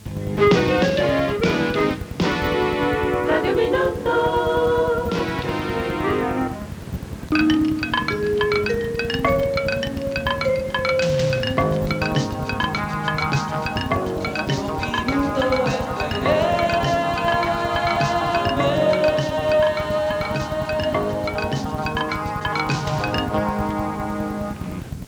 Dos indicatius de l'emissora.